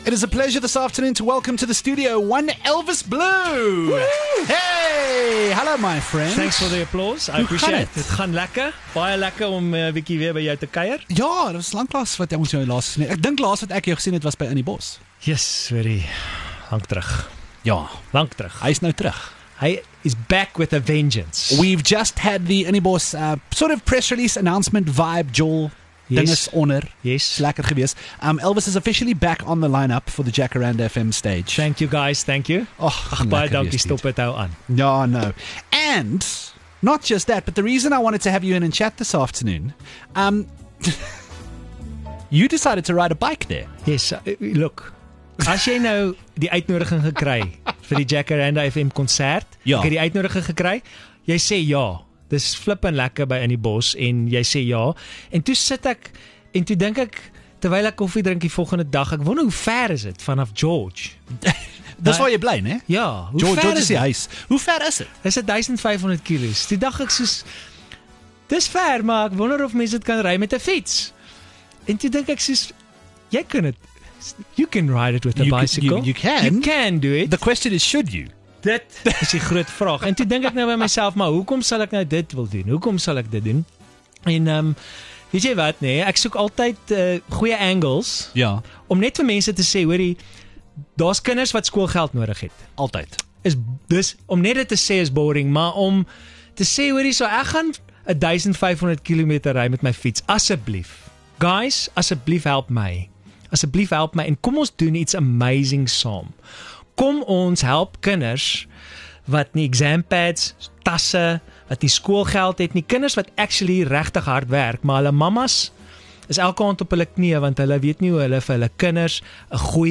Elvis Blue stopped by the studio to chat about his return to Innibos.